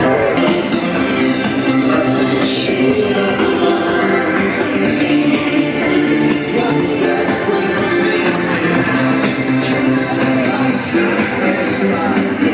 Hyde Park for the Olympics closing party.